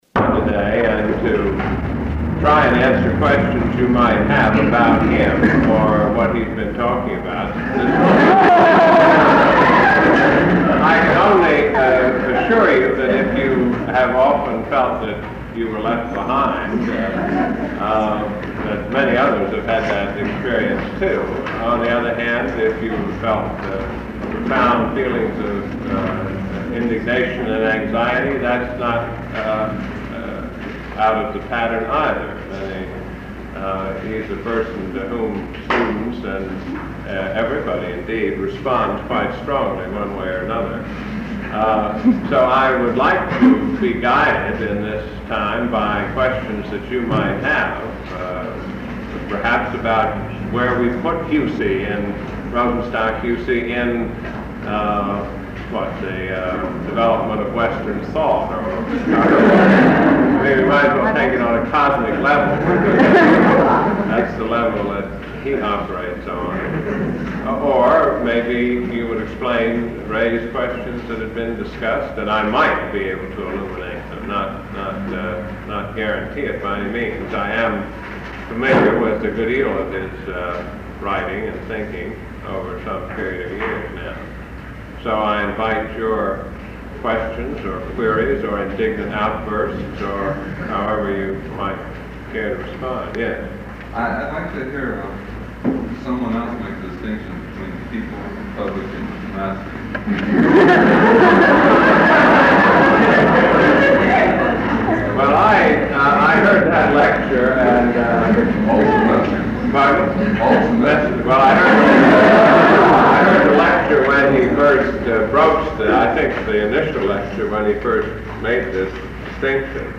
It preserves Page Smith taking questions on Rosenstock-Huessy and the course he had just finished teaching, and so is a unique record of the inspiration across generations on which Rosenstock-Huessy focused so frequently in his lectures.